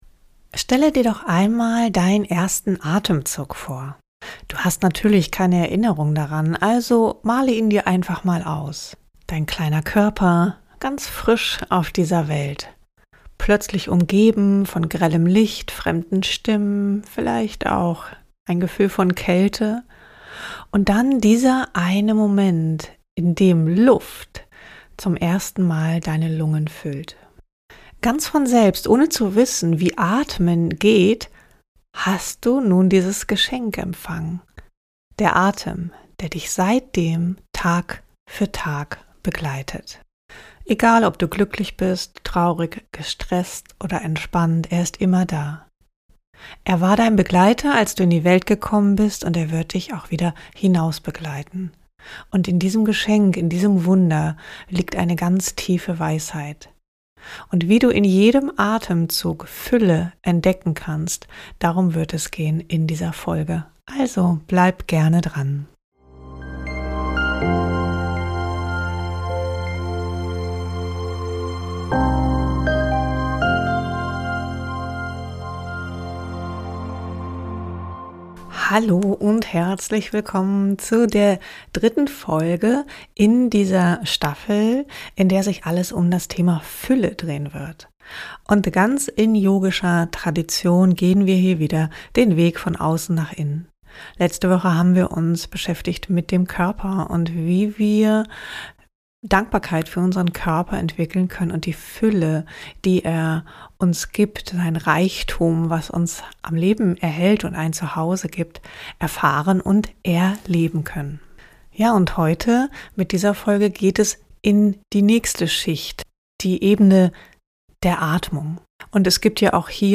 Als Bonus wartet eine angeleitete Atempraxis auf dich: eine sanfte Übung, mit der du die Atempausen direkt in deinem Körper erleben kannst – egal, wo du gerade bist.